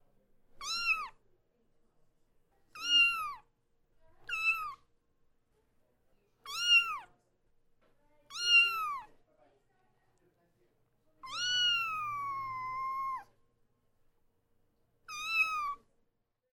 kittens-meow-sound